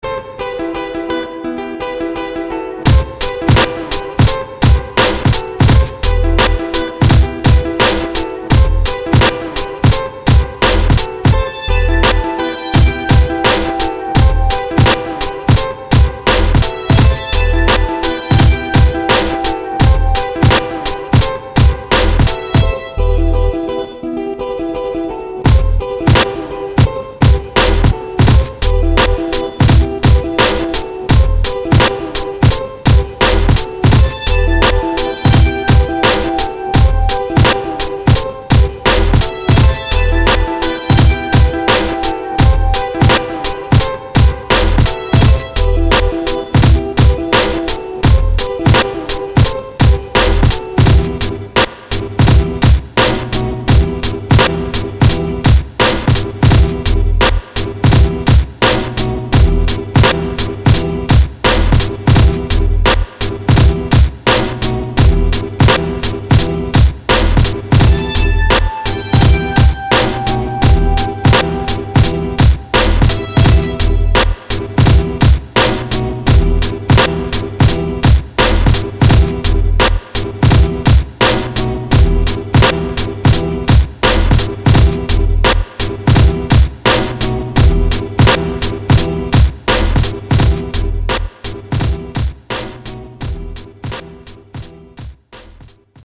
epic